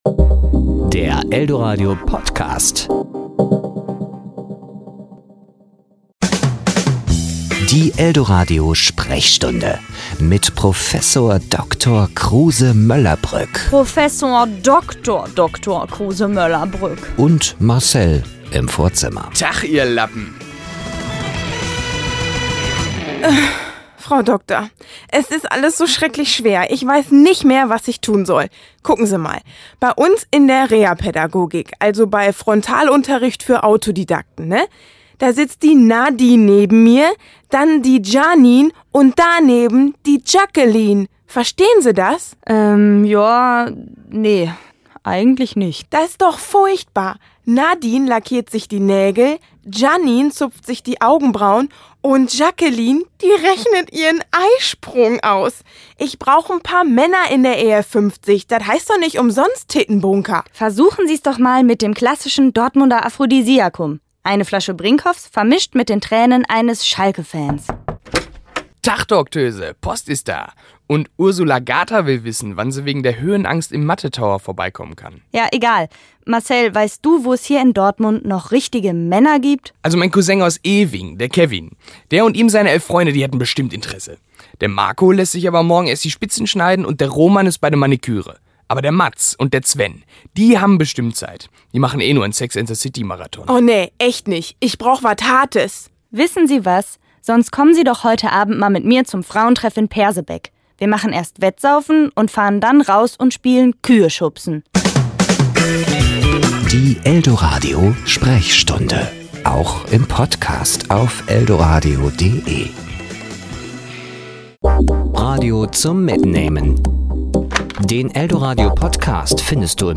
Comedy  Sendung